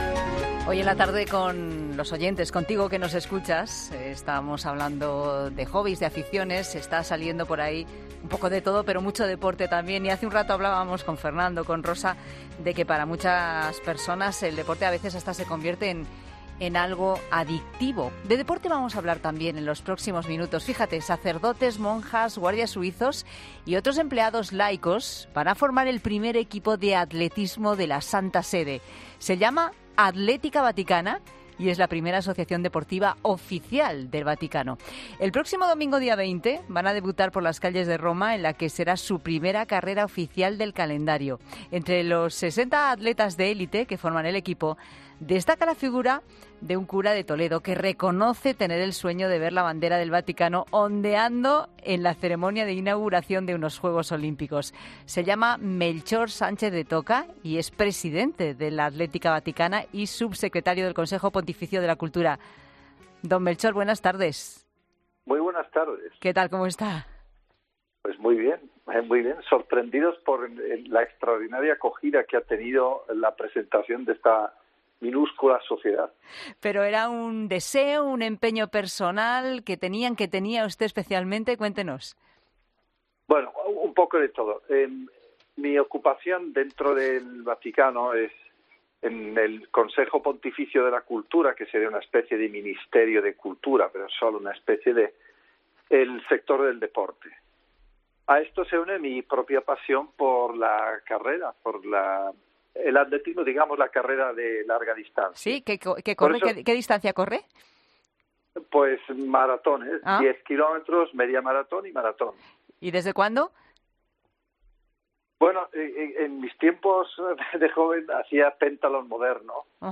Él es Melchor Sánchez de Toca, presidente de Athlética Vaticana y subsecretario del Consejo Pontifico de la Cultura, y ha estado en 'La Tarde' para contarnos más cosas sobre esta bonita ilusión.